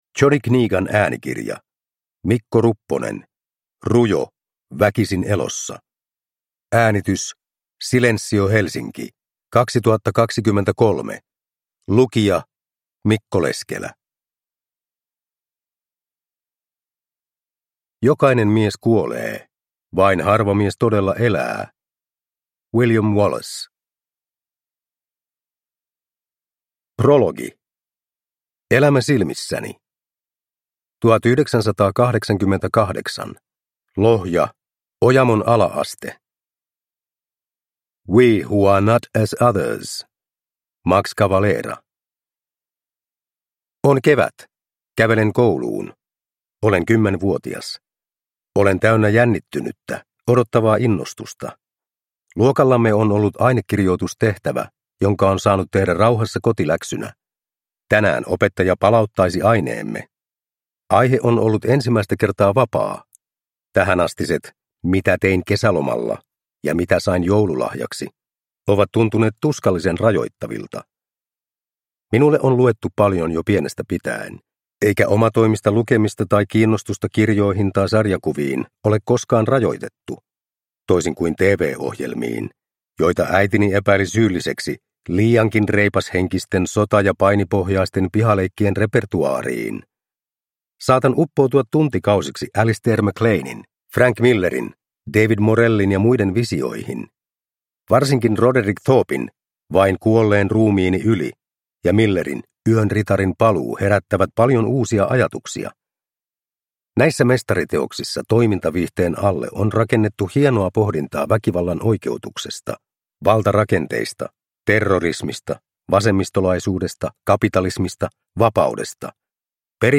Rujo – Ljudbok